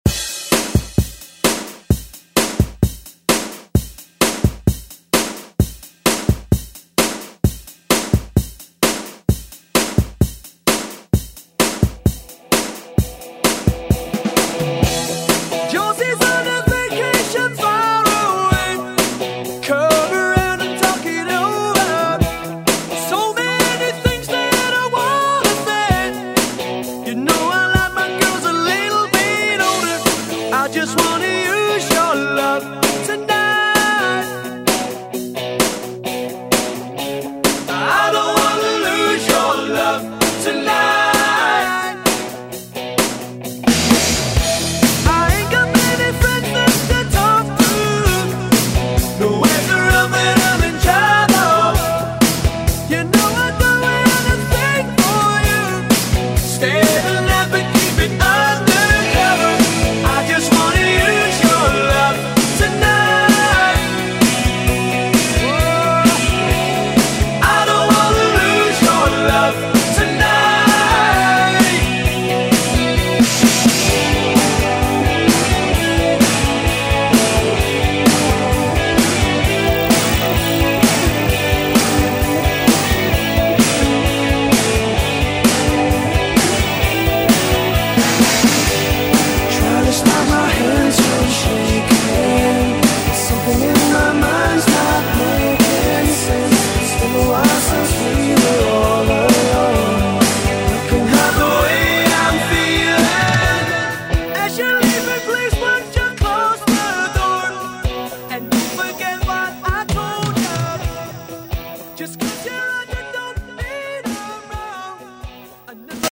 Genres: 2000's , HIPHOP , RE-DRUM
BPM: 88